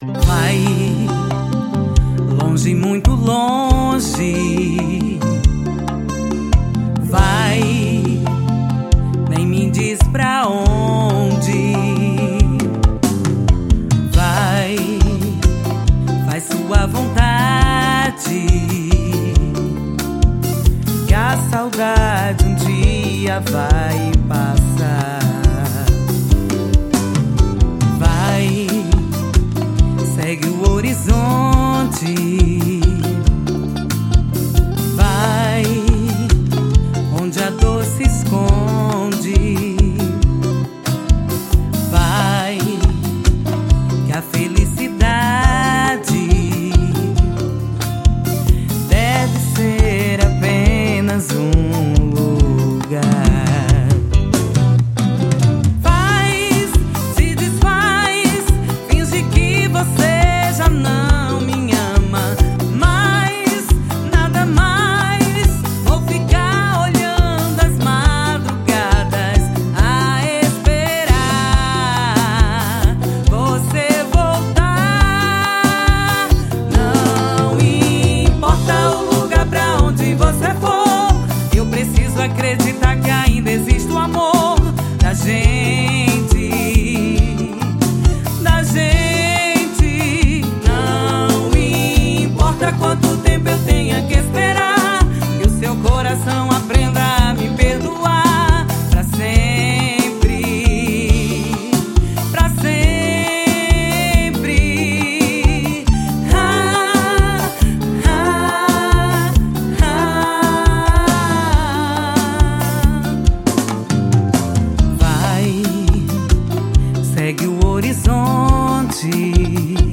Arrocha 2013.4. Esta informação está incorreta?